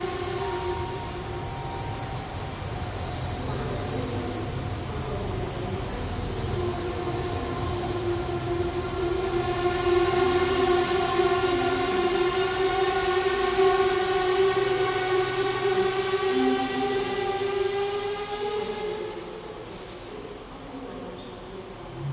火车站1
描述：克拉科夫火车站的氛围
Tag: 铁路 铁路 铁路车站 列车 车站 铁路 火车